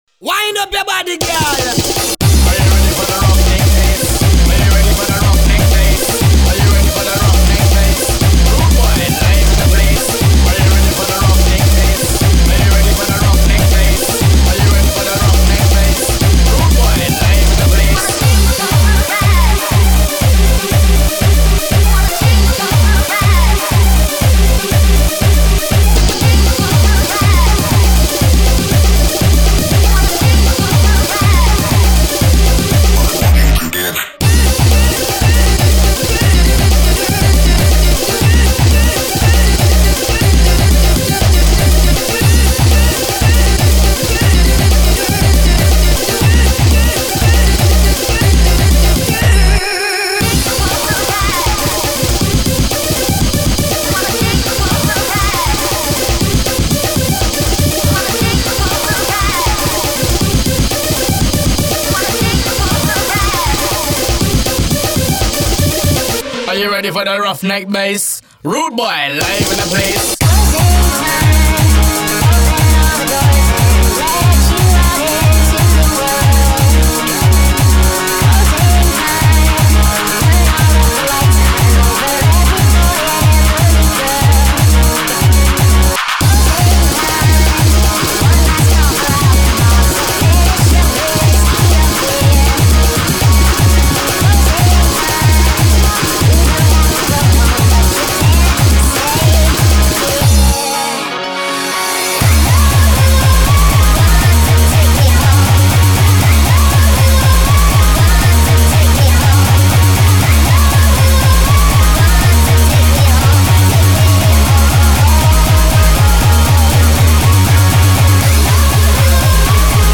Index of: /data/localtracks/Japanese/J-Core/